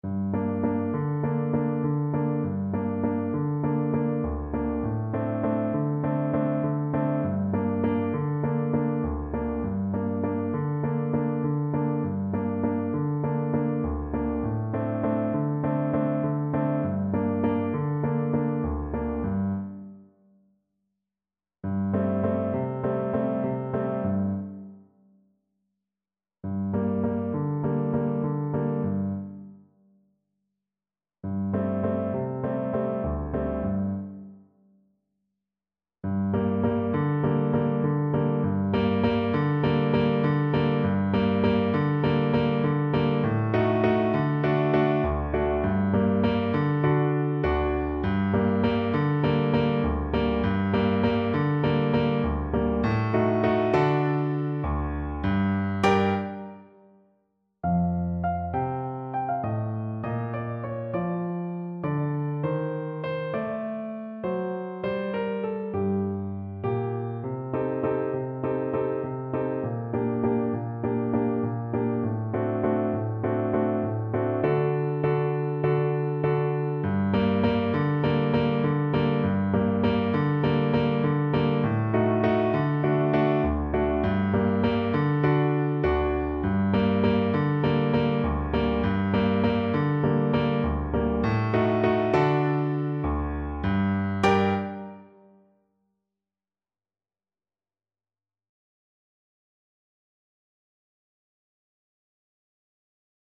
A sultry and atmospheric piece.
Moderato
8/8 (View more 8/8 Music)
World (View more World Flute Music)